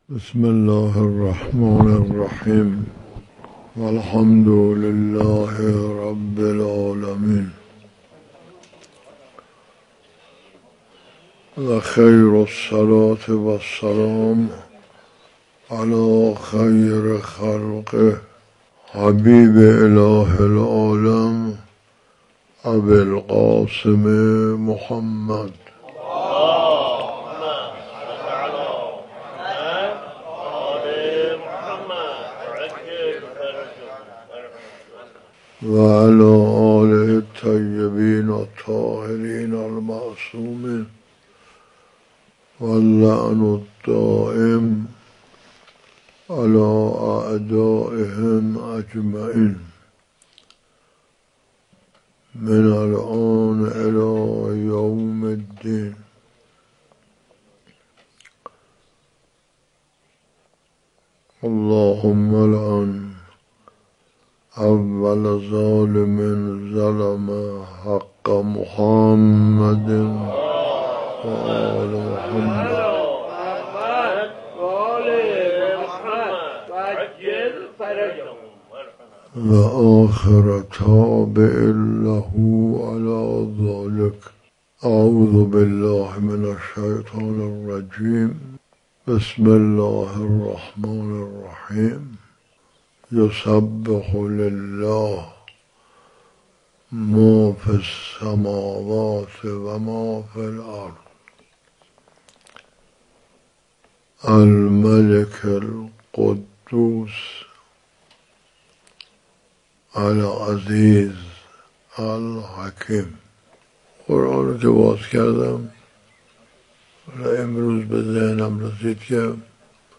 صوت سخنرانی
تفسیر-سوره-جمعه.m4a